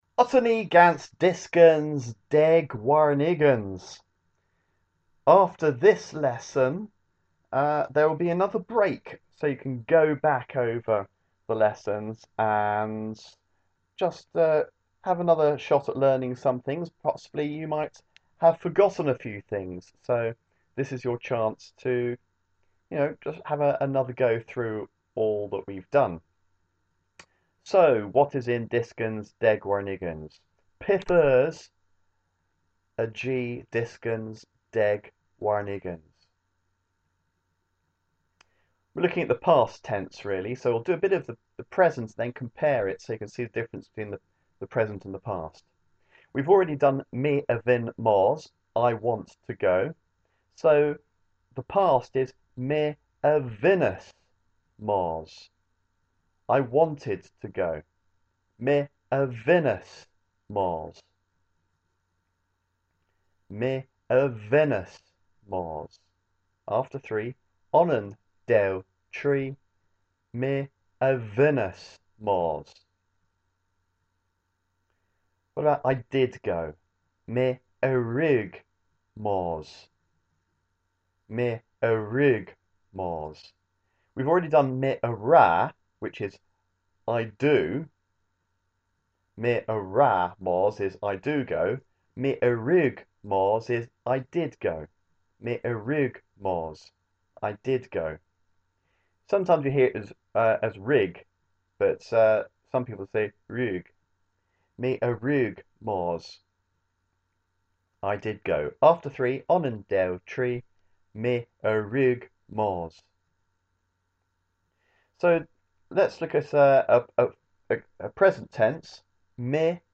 Cornish lesson 30 - dyskans deg warn ugens